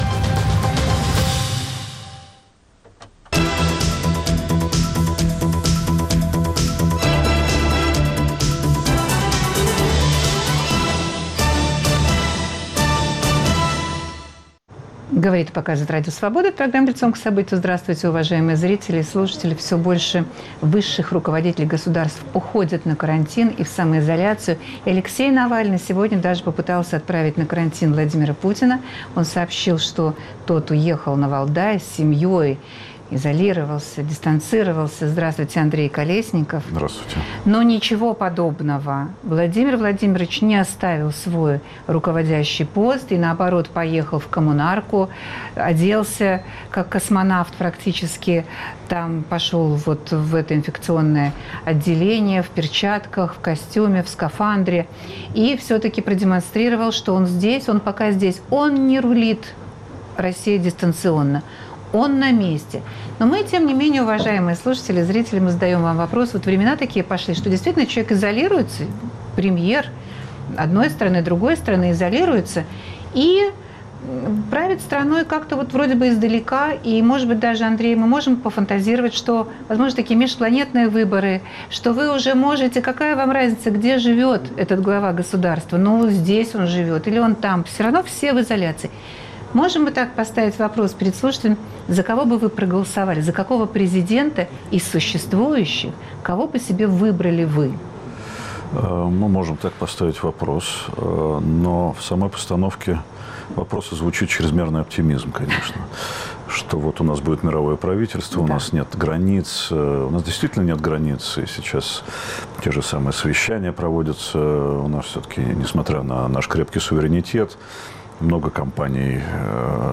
Вы за какого из глав государств проголосовали бы на всемирных дистанционных выборах? В гостях аналитик